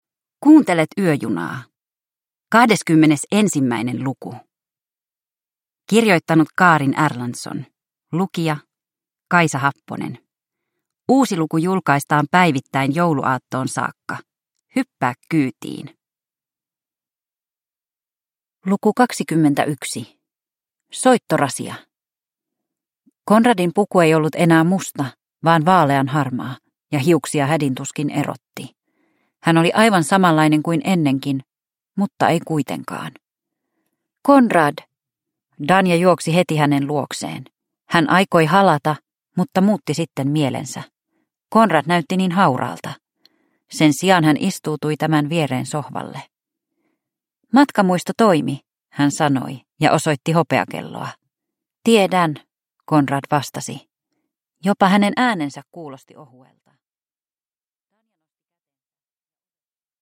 Yöjuna luku 21 – Ljudbok